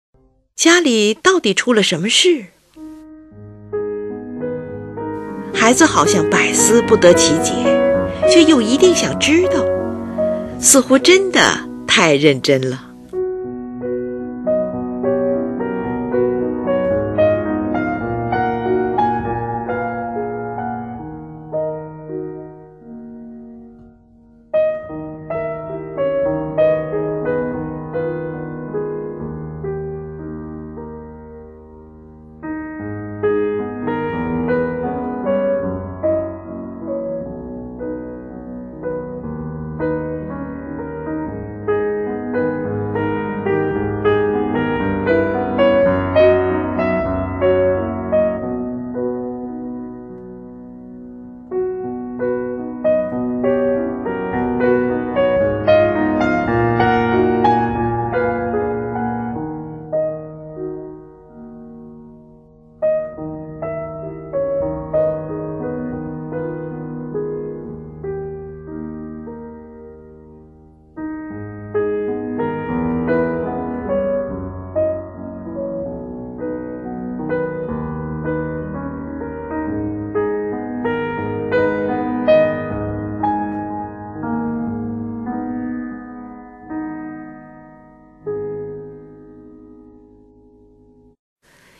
乐曲中绵绵不断的切分旋律，妥帖地写出了孩子百思不得其解的神情。